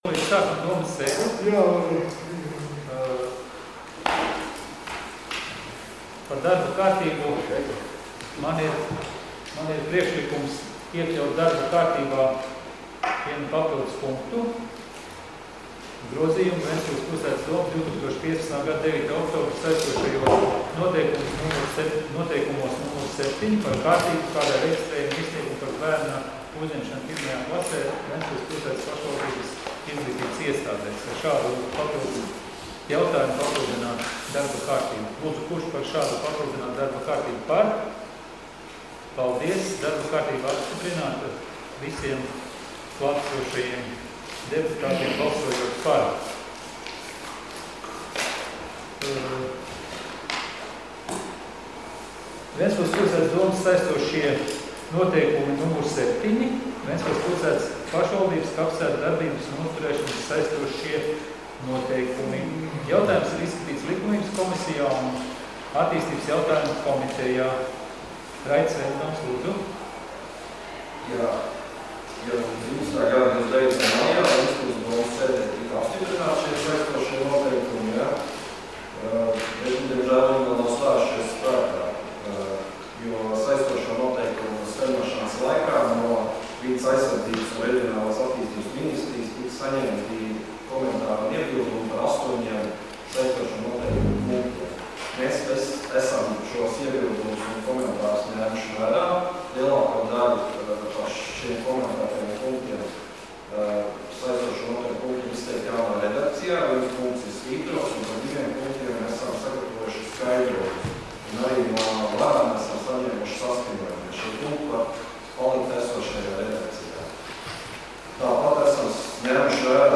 Domes sēdes 22.12.2020. audioieraksts